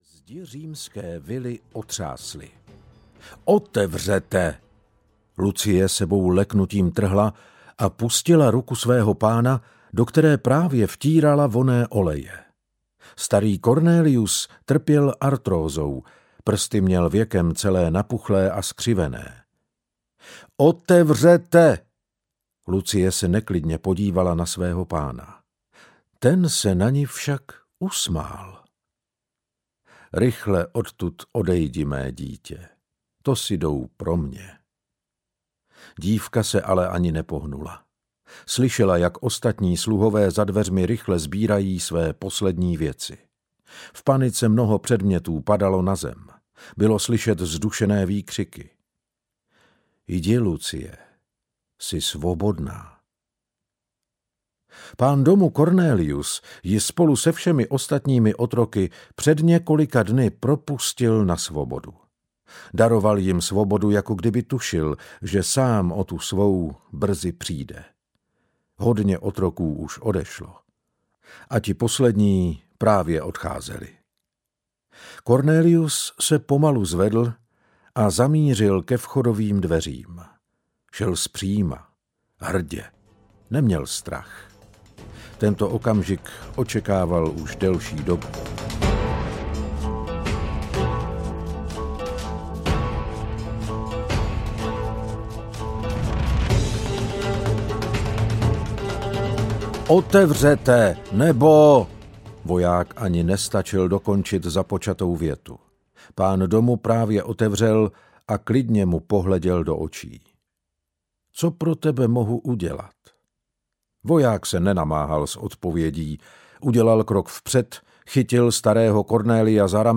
Audio knihaPřízrak z Kolosea
Ukázka z knihy